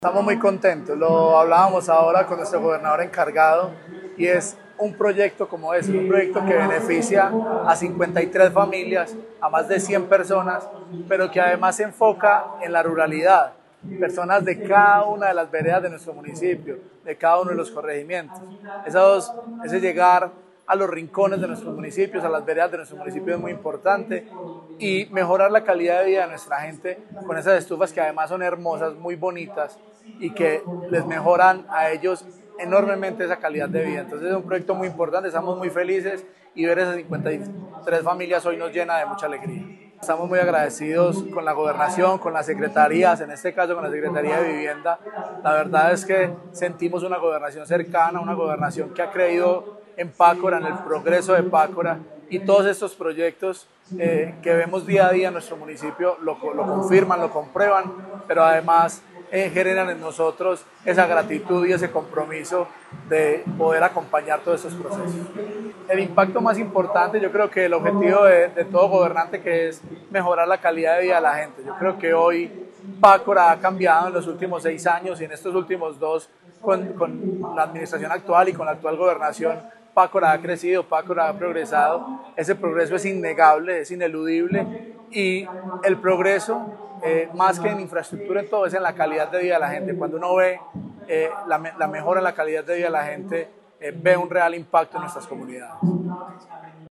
Juan Camilo Isaza González, alcalde Pácora.